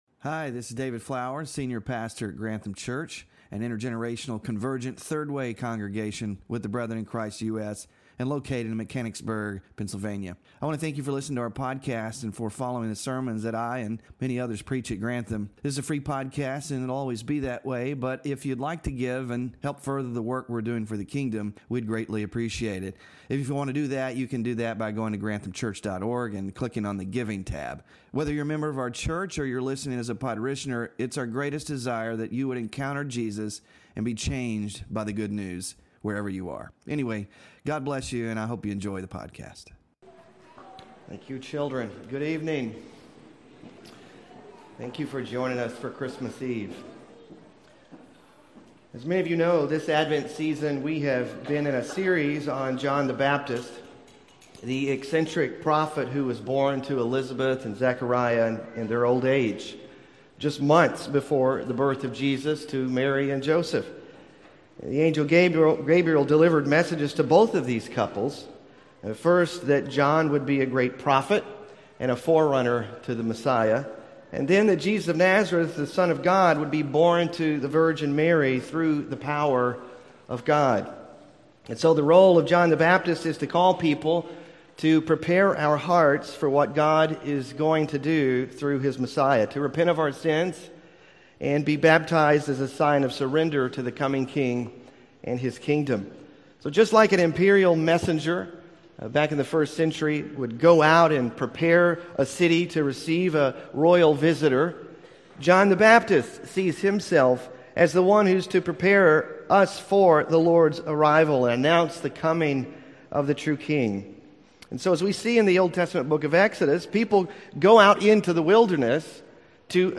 PREPARE THE WAY FOR THE LORD Scripture Reading: John 1:1-14 Sermon Focus: The Word became flesh—God with us!